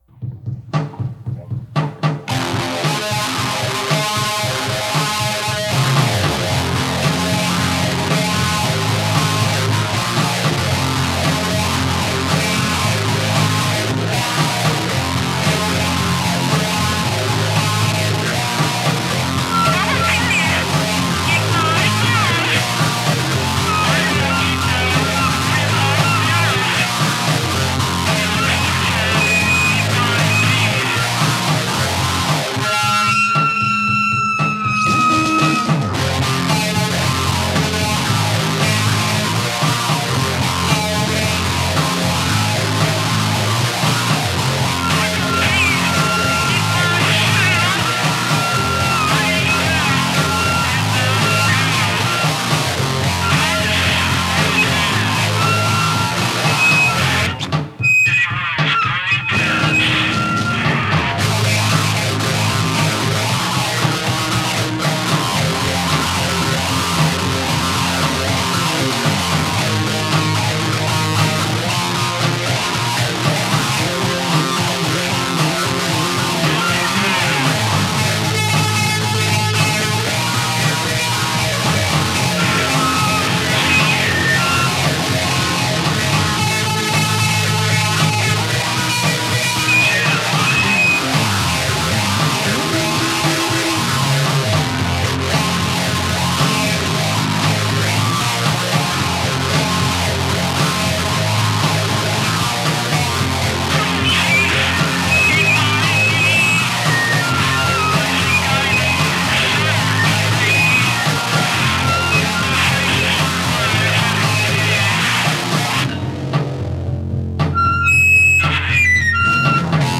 recorded live
guitar, bass
bass, vocals, autovari64, mirage
phase guitar